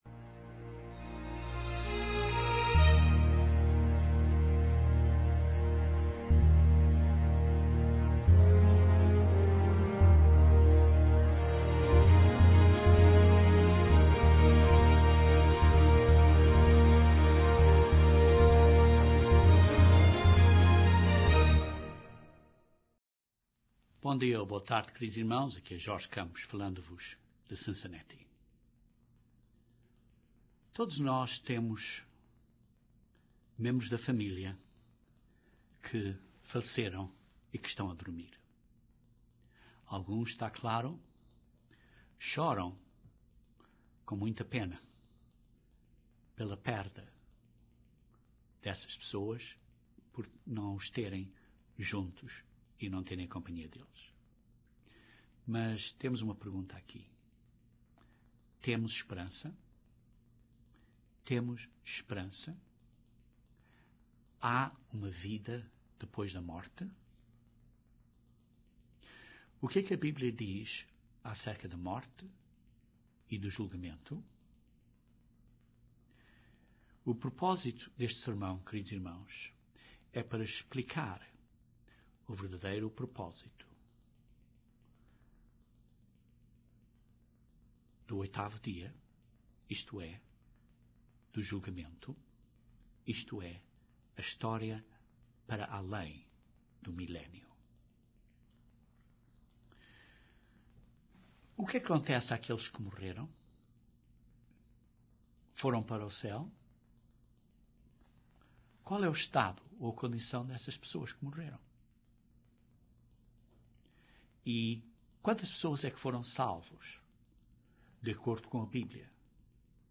Este sermão - ideal para ser ouvido no oitavo dia da Festa, descreve o significado do dia.